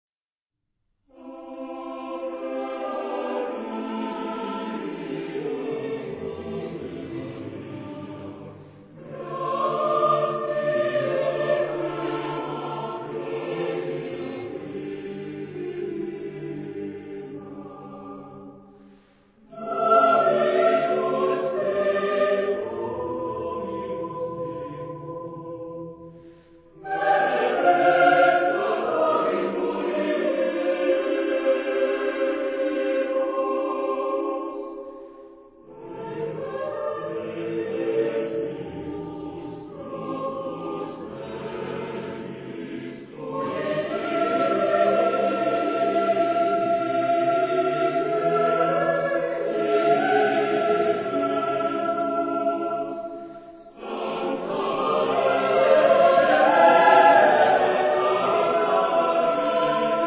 Epoque: 20th century  (1980-1989)
Genre-Style-Form: Choir ; Sacred
Mood of the piece: andante
Type of Choir: SMA  (3 women voices )
Tonality: C major